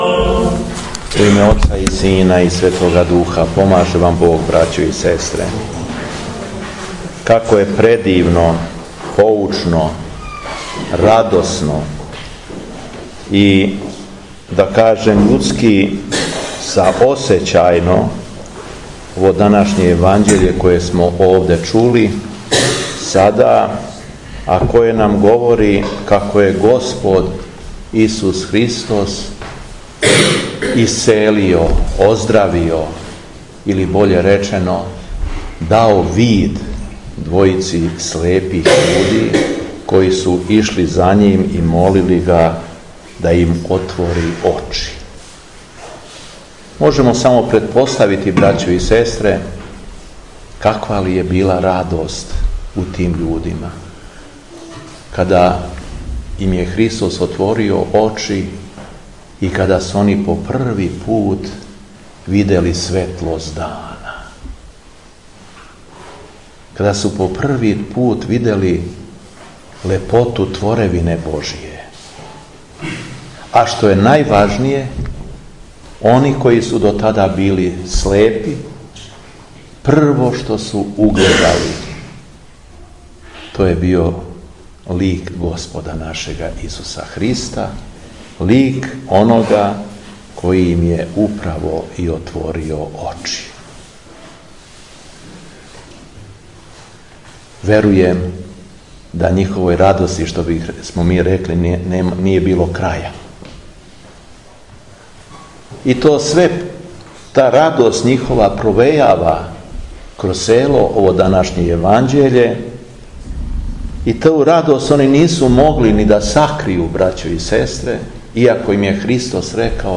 Беседа Епископа шумадијског Г. Јована
На празник Св. Марије Магдалине, 4. августа 2019. године, Његово Преосвештенство Епископ шумадијски Г. Јован служио је Свету архијерејску Литургију у манастиру Драча, поводом славе манастирског параклиса.